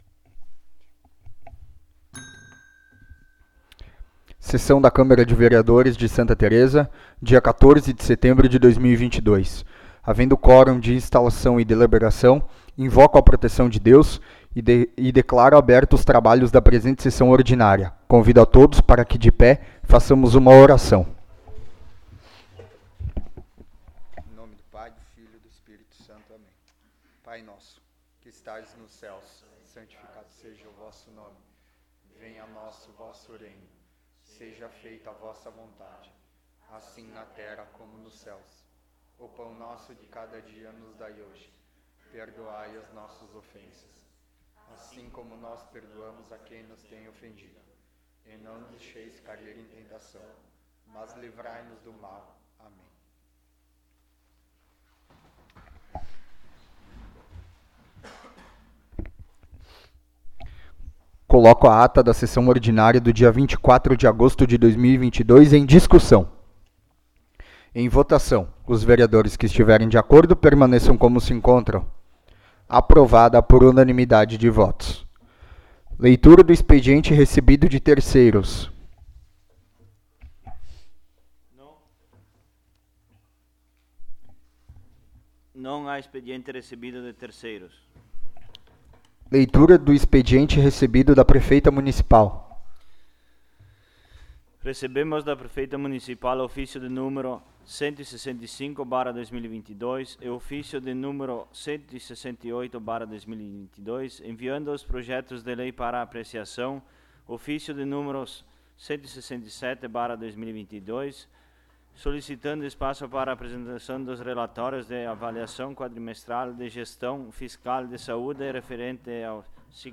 15ª Sessão Ordinária de 2022
Áudio da Sessão